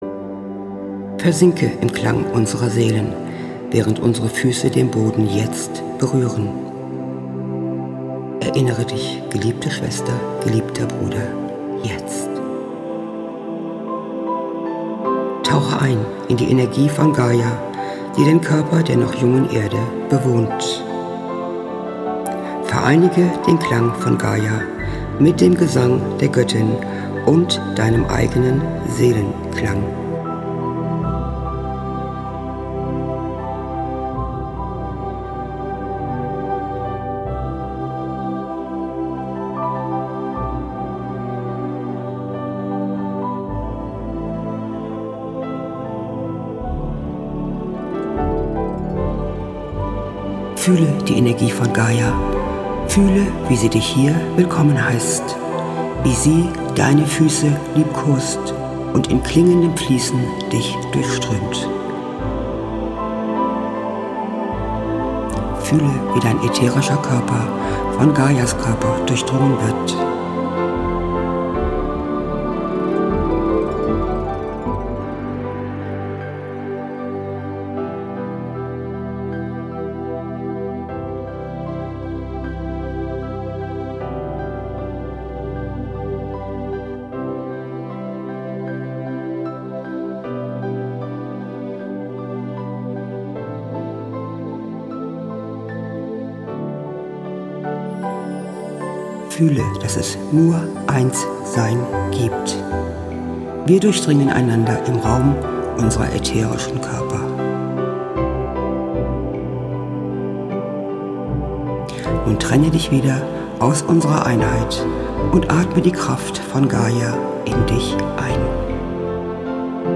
Diese Meditationen, untermalt mit traumhaft schöner Musik, können dich ganz tief zu deinem wahren Selbst führen.